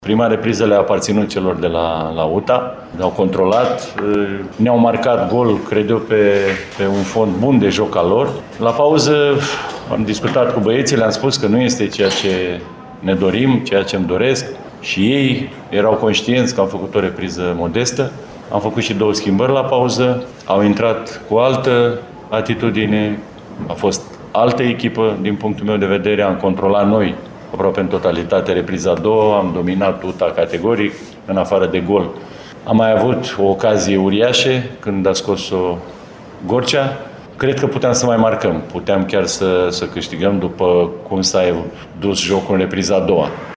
De partea cealaltă, antrenorul ploieștenilor, Eugen Neagoe, a povestit cum ”ședința” din vestiar și modificările făcute au schimbat în bine angrenajul oaspeților: